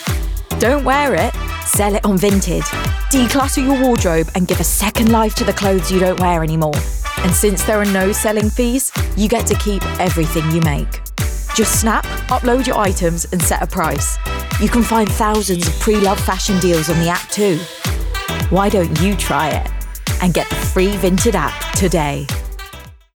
RP ('Received Pronunciation')
Commercial, Bright, Upbeat, Conversational